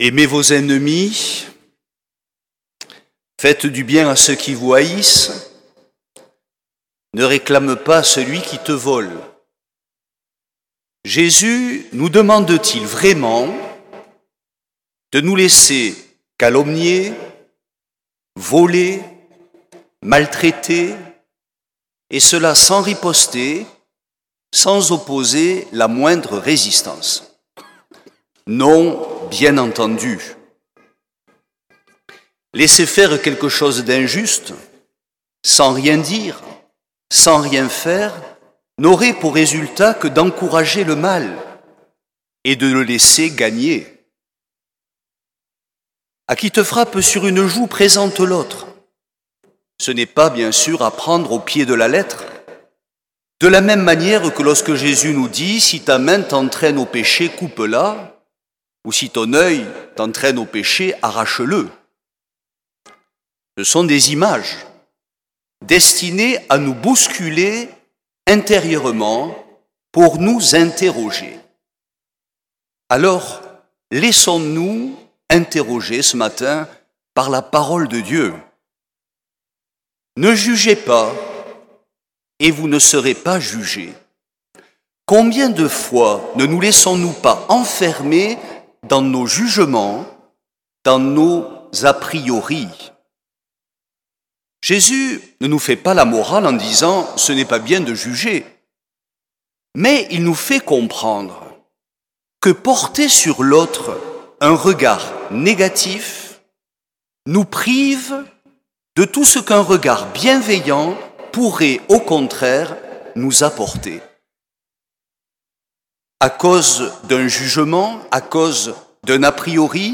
Homélie
7ème dimanche du temps ordinaire